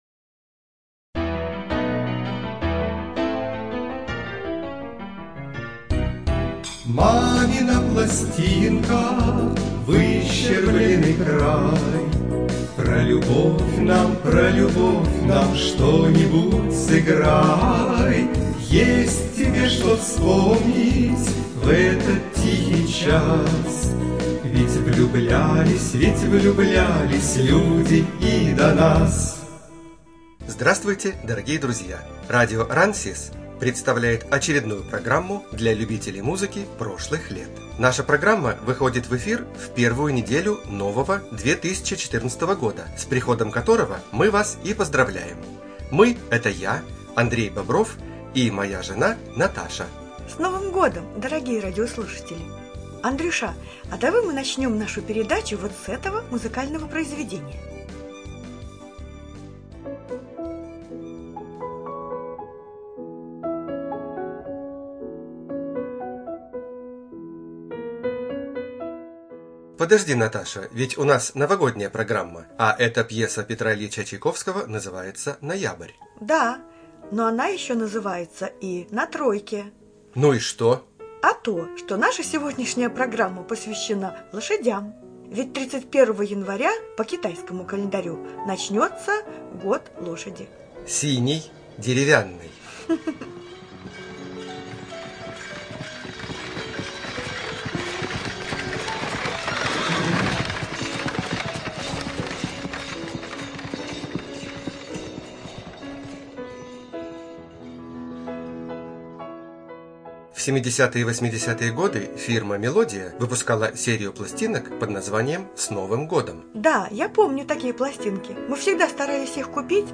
ЖанрРадиопрограммы